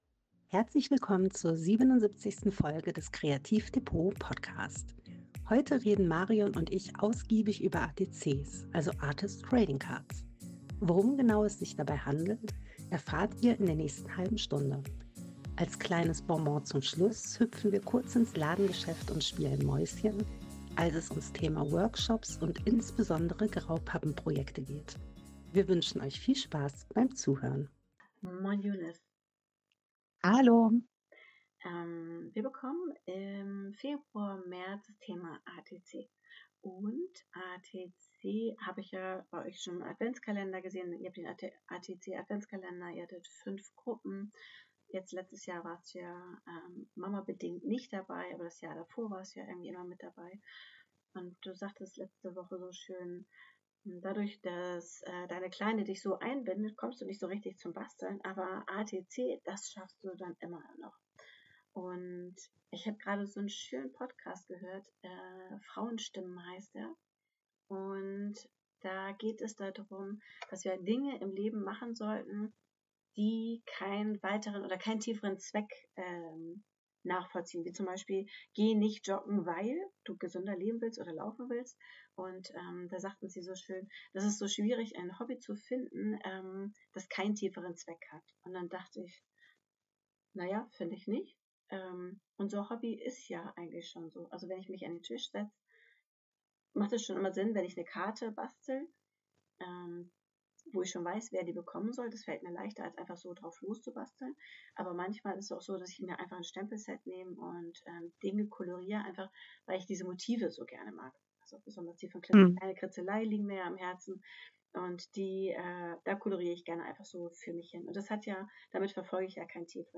Worum genau es sich dabei handelt und warum ich sie so sehr liebe, erfahrt ihr in der nächsten halben Stunde. Als kleines Bonbon zum Schluss hüpfen wir kurz ins Ladengeschäft und spielen Mäuschen, als es ums Thema Workshops und insbesondere Graupappeprojekte geht.